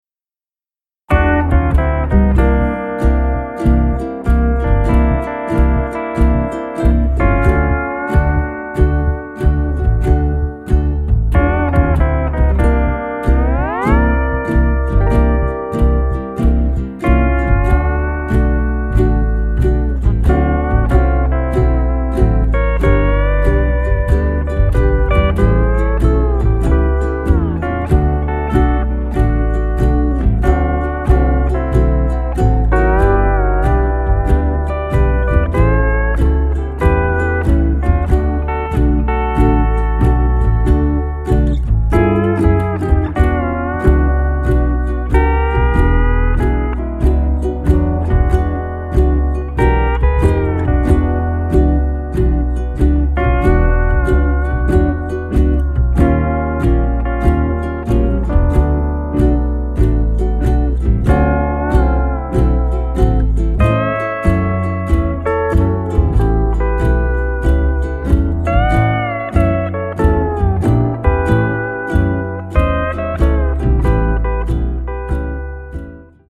Sand on Hawaiian Steel Guitar